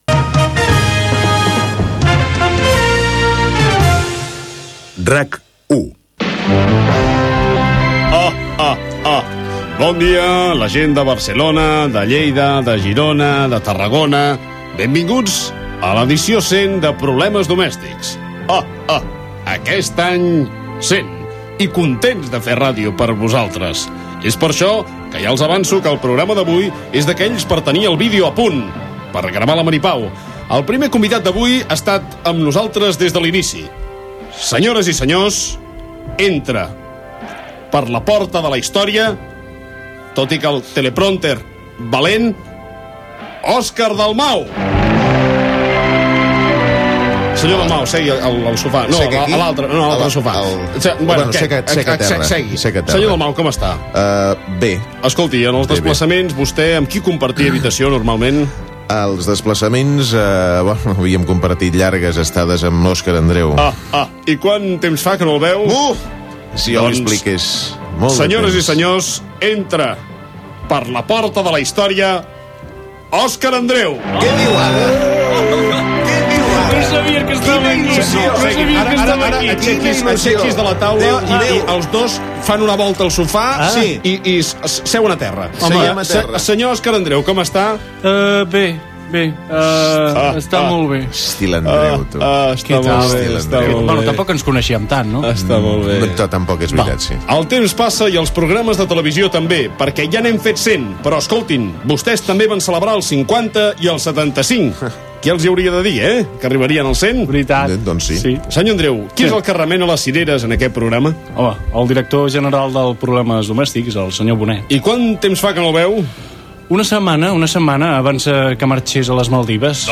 Indicatiu de l'emissora i inici del programa on hi ha la presentació dels col·laboradors. Finalitza amb la paròdia d'una trucada del President Jordi Pujol.
Entreteniment